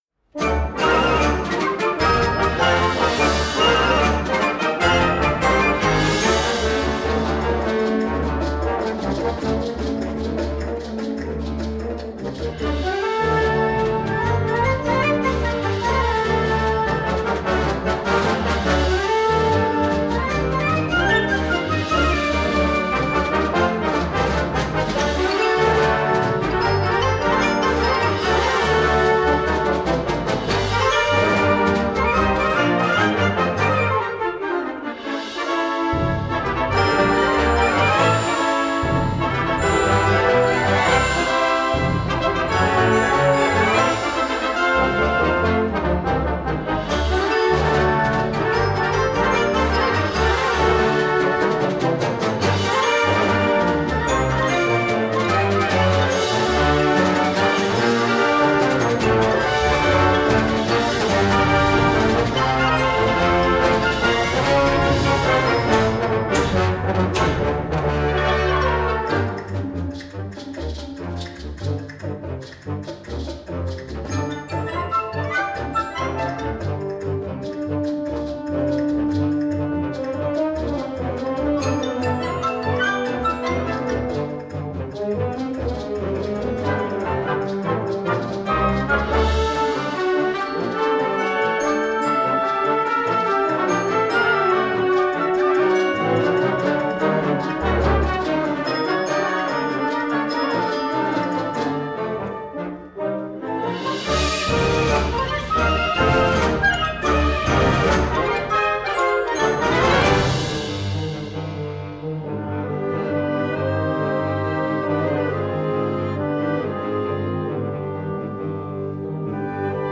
Gattung: Konzertwerk für Blasorchester
Besetzung: Blasorchester